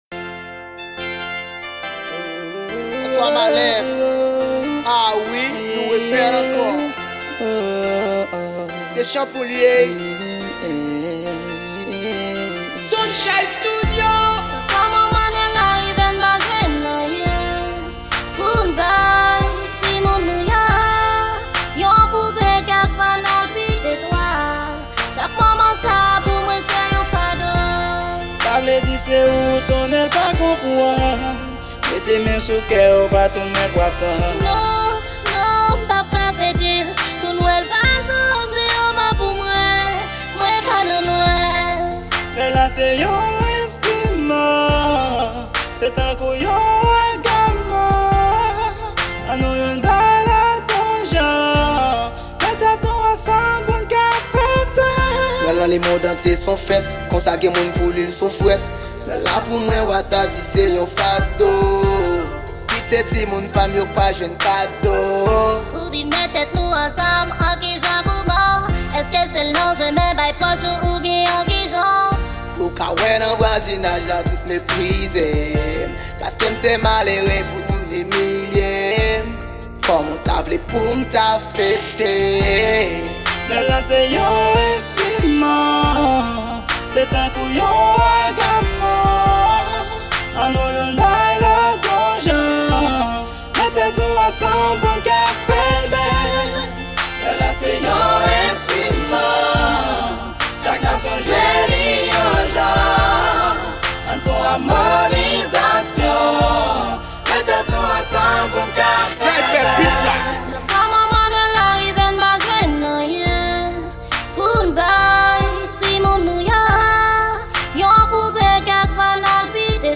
Genre: R&B.